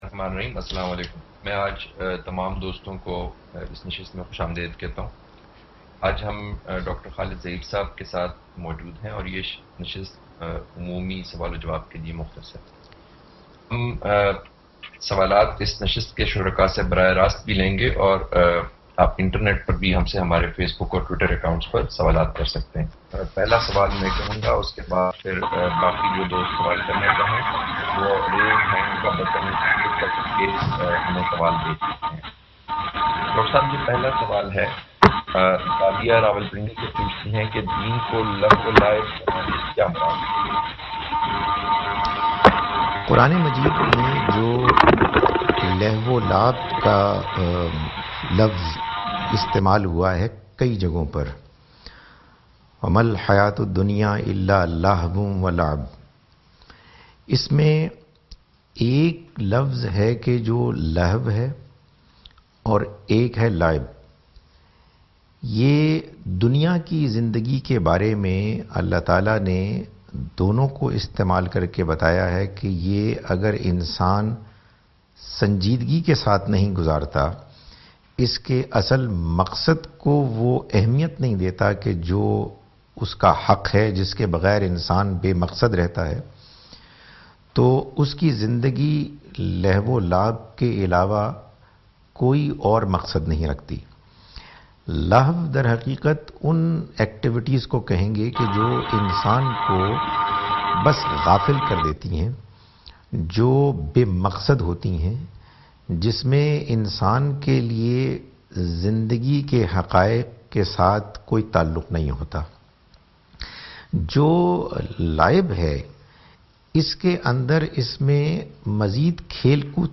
Question Answer Session
Questions and Answers-Webex session